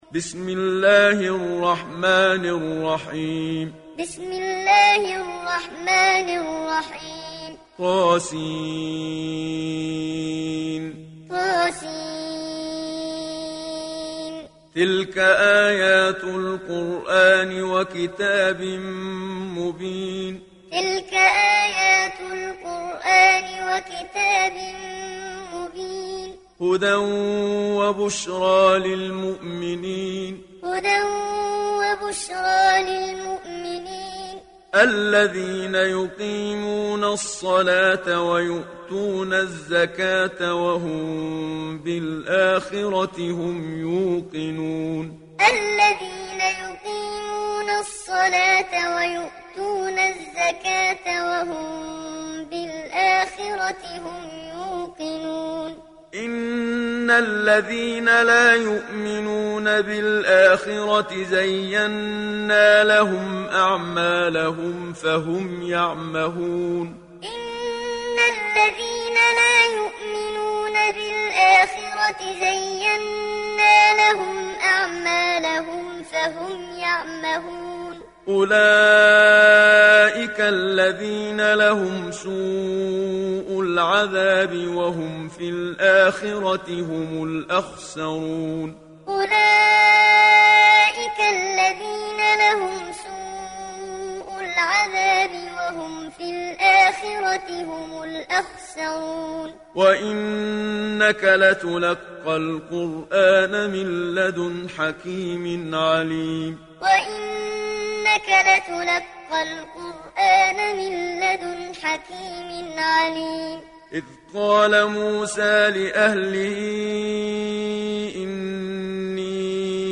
دانلود سوره النمل محمد صديق المنشاوي معلم